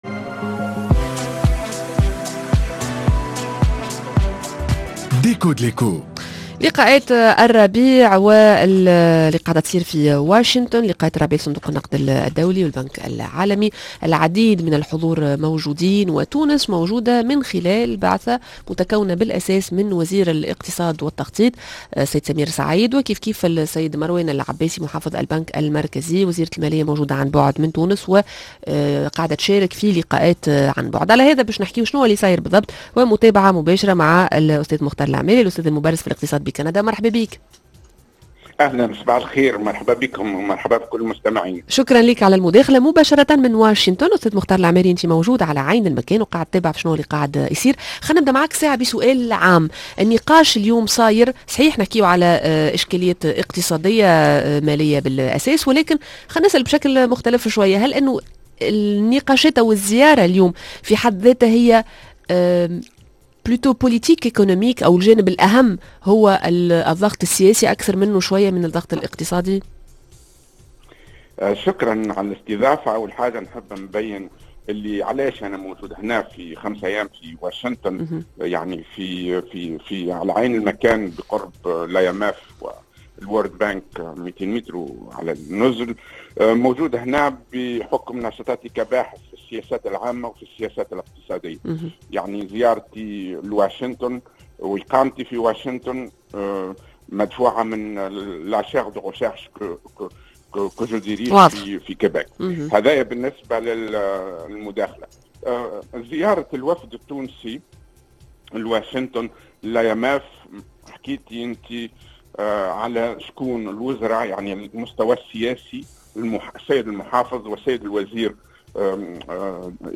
Déco de l'éco: مباشرة من واشنطن